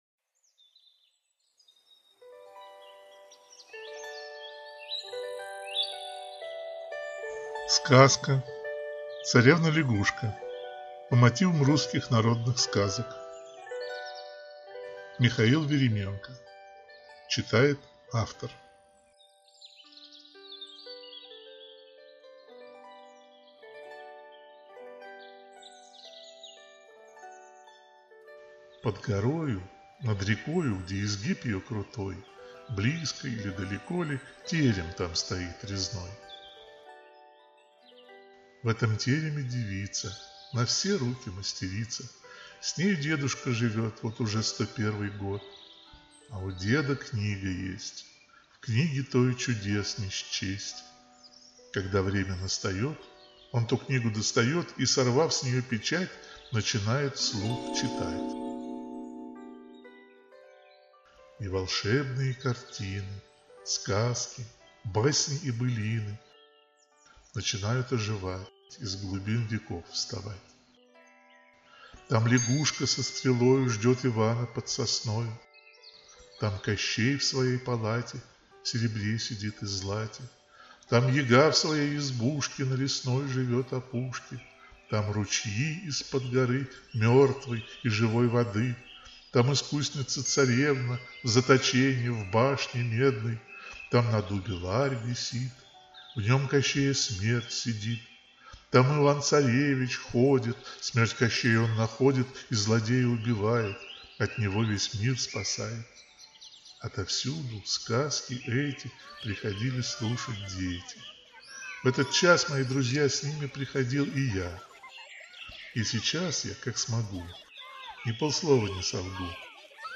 Аудиокнига Царевна-лягушка.